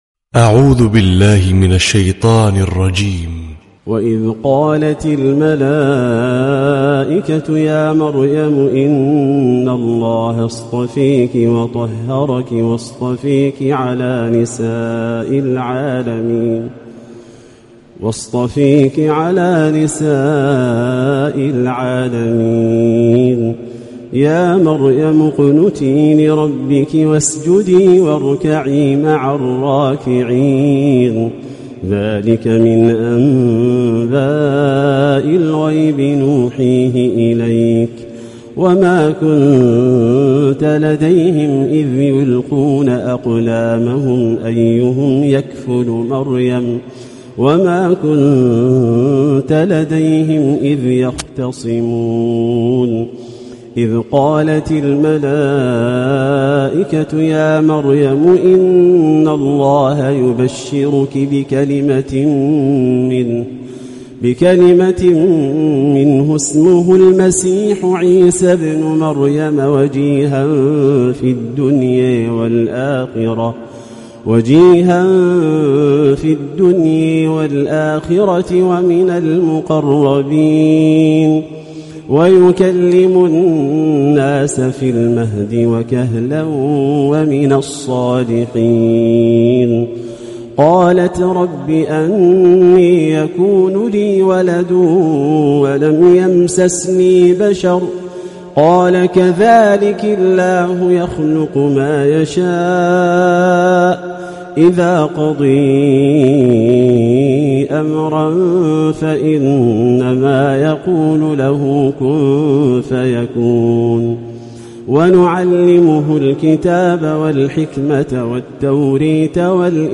🌙🕌•تلاوة مسائية•🕌🌙
★برواية خلف العاشر★